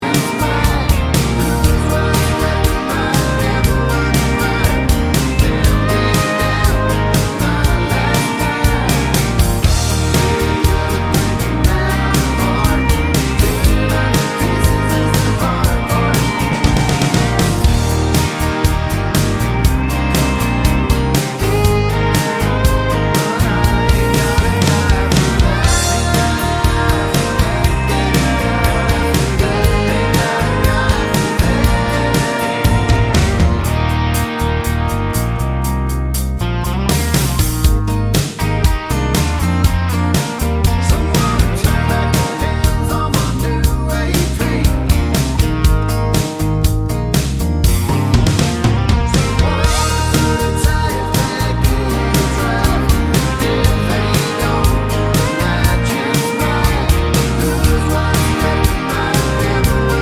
no Backing Vocals Country (Male) 2:42 Buy £1.50